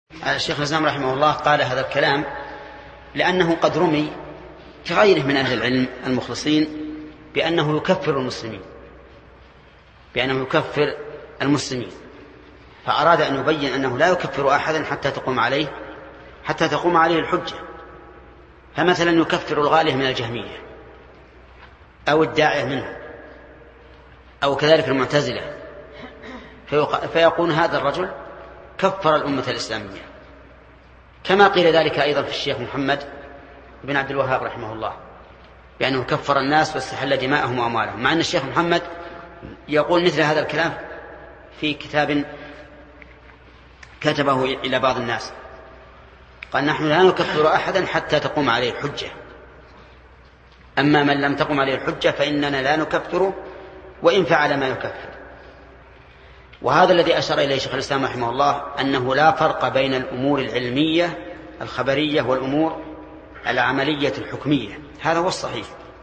Format: MP3 Mono 22kHz 32Kbps (VBR)